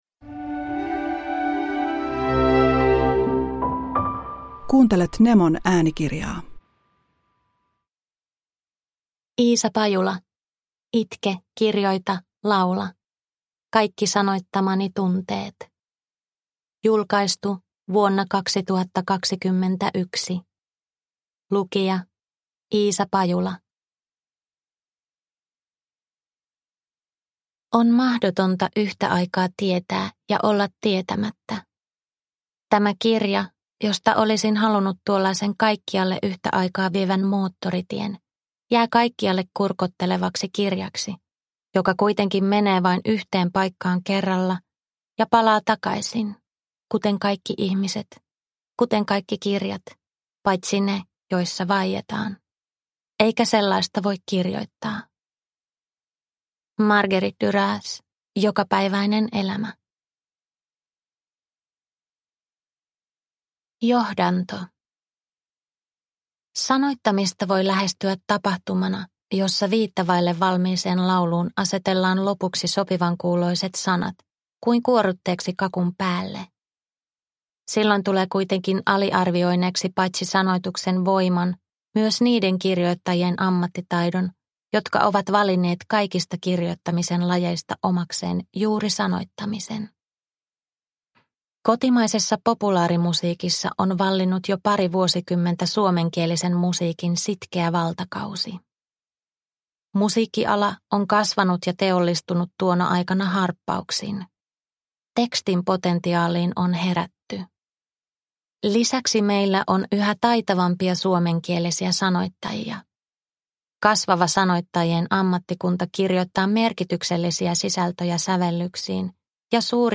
Itke kirjoita laula – Ljudbok – Laddas ner